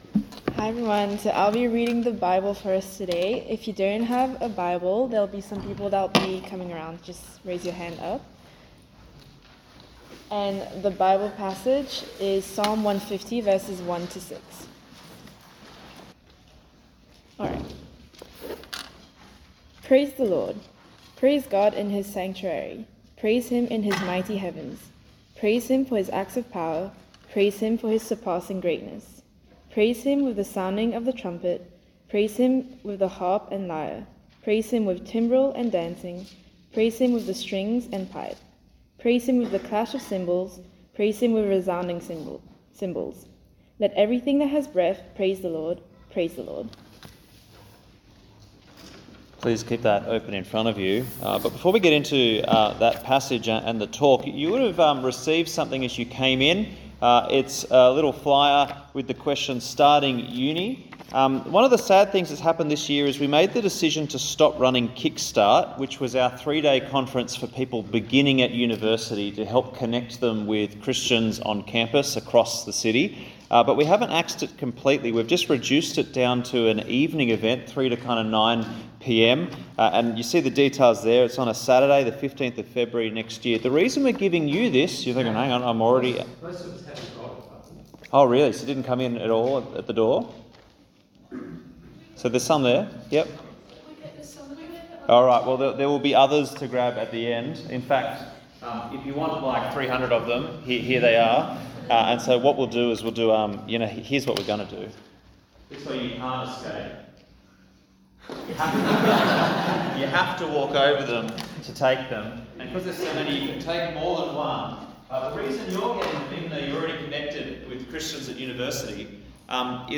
Bible talks from the Christian Union at the University of Western Australia.